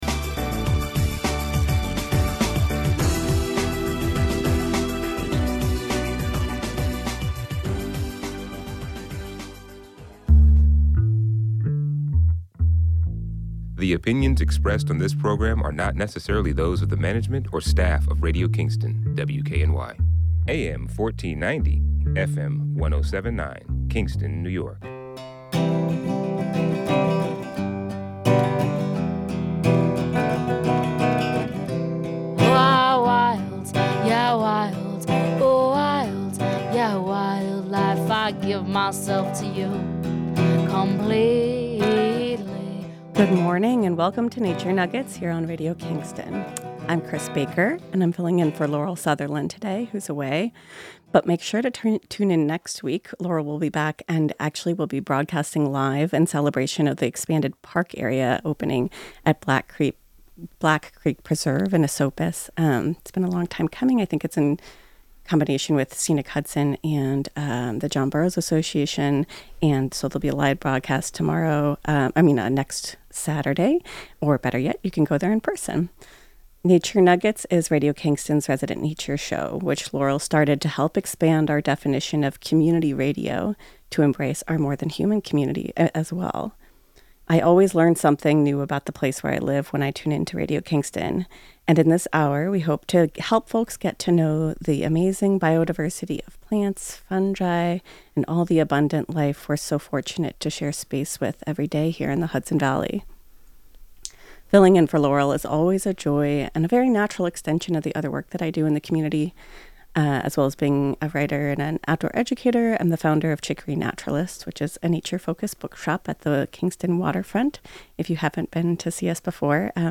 fireside chat